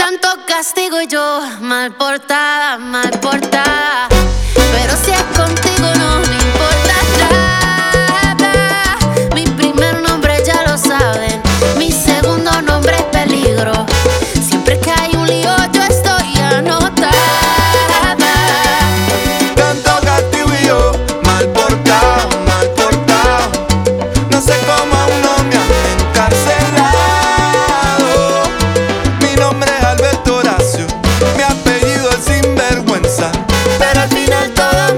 Música tropical, Latin